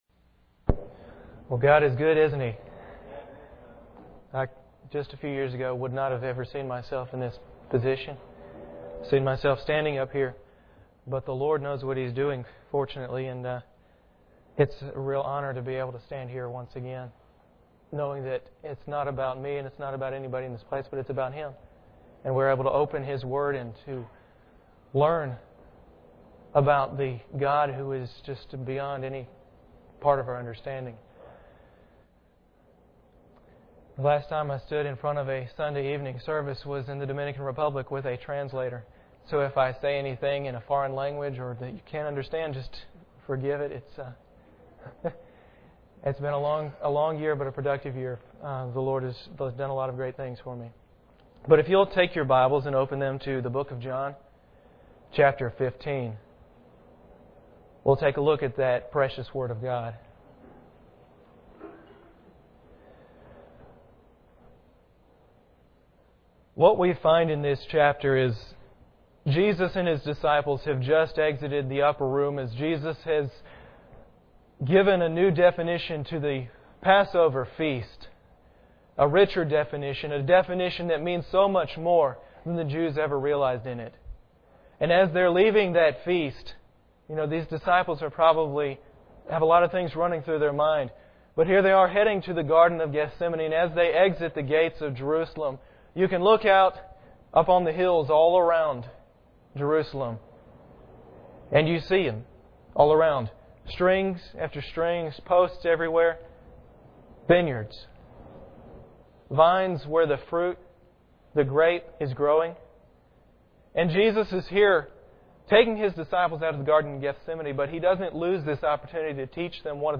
John 15:1-5 Service Type: Sunday Evening Bible Text